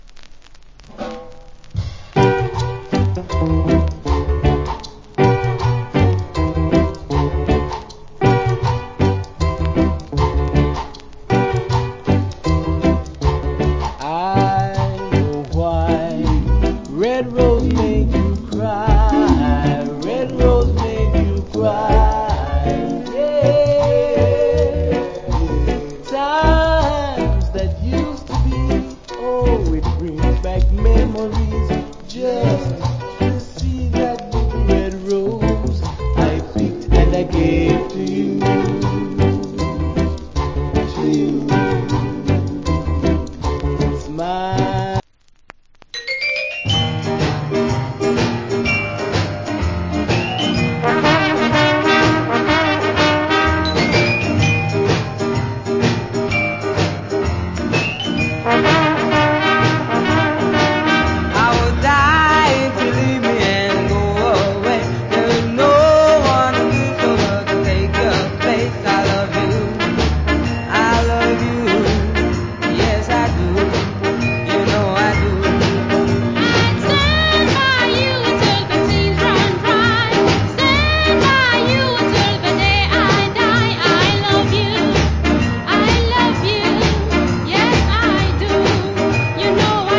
Cool Rock Steady Vocal.